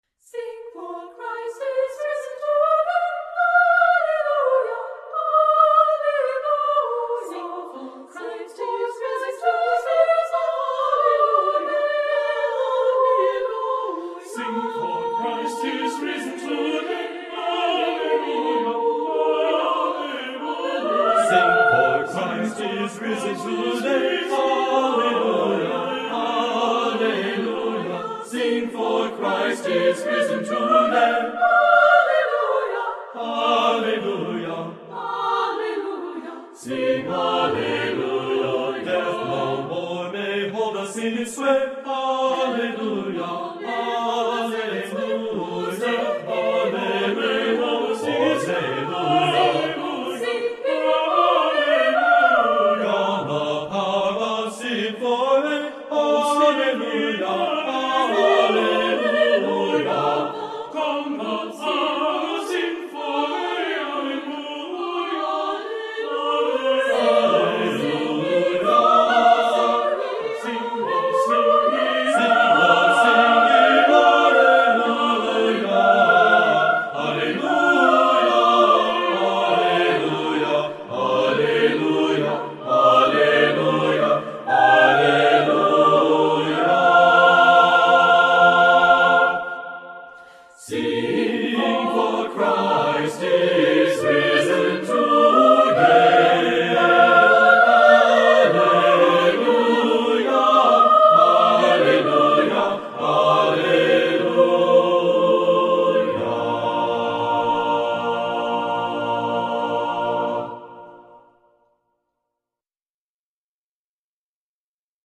Voicing: SATB a cappella